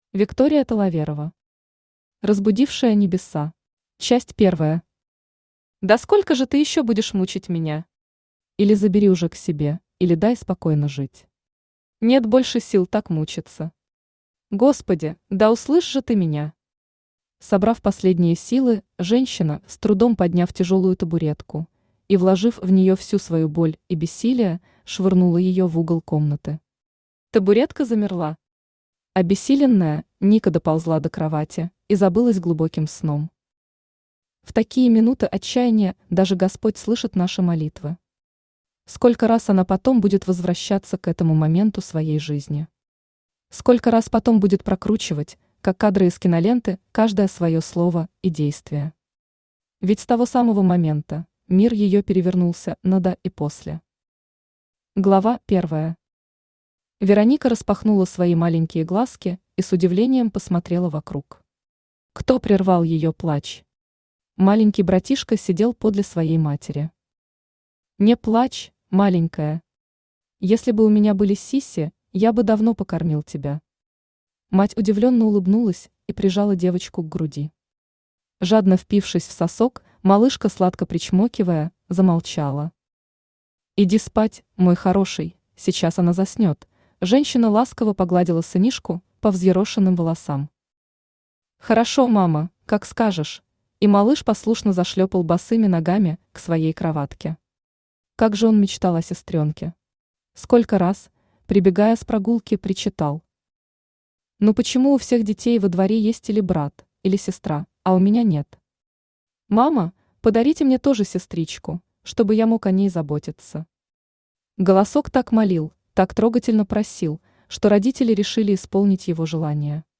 Аудиокнига Разбудившая небеса | Библиотека аудиокниг
Aудиокнига Разбудившая небеса Автор Виктория Владимировна Таловерова Читает аудиокнигу Авточтец ЛитРес.